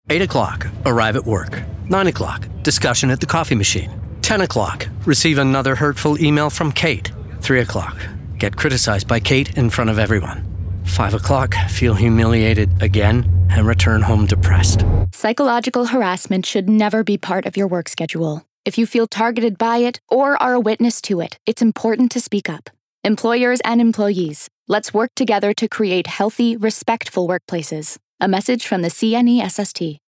Commercial (CNESST) - EN